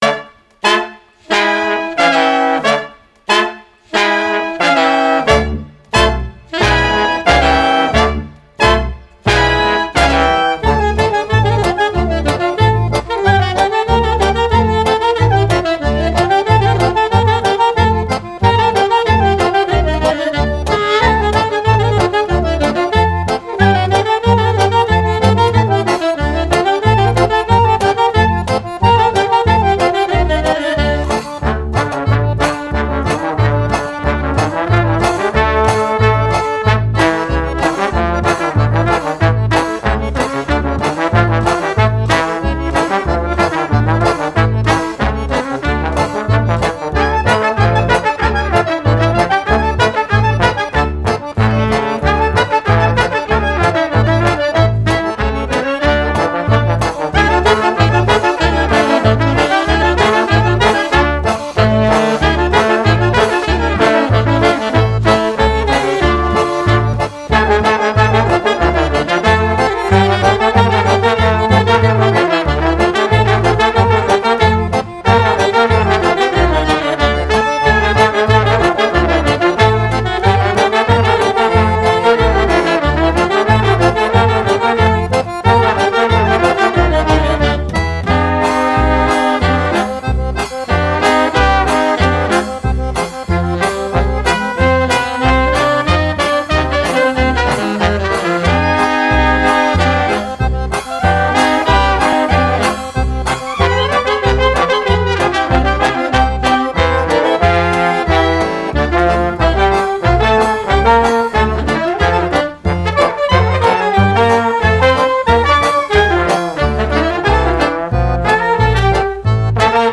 Genre: Klezmer, Folk, World